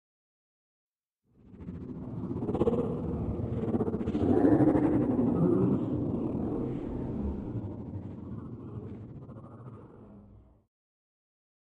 Alien Talking 3 - Big Reverb - Short